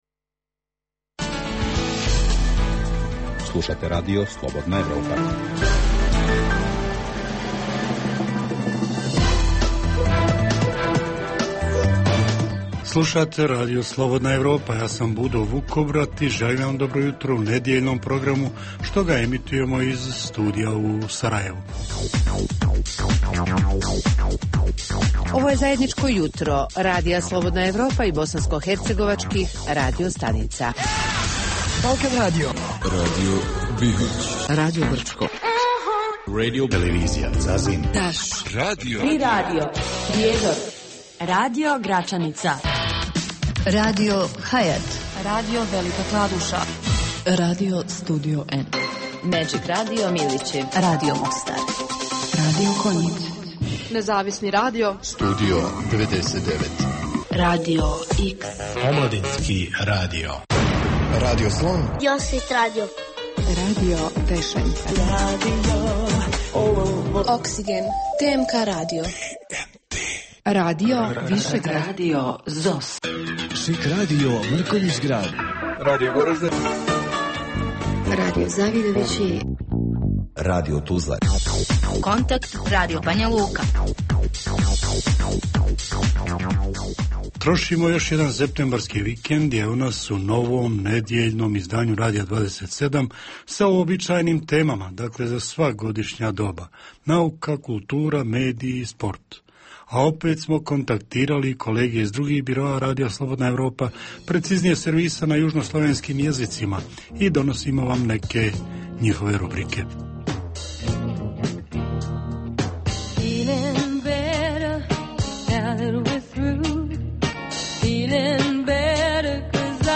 Jutarnji program namijenjen slušaocima u Bosni i Hercegovini. Sadrži intervju, te novosti iz svijeta nauke, medicine, visokih tehnologija, sporta, filma i muzike.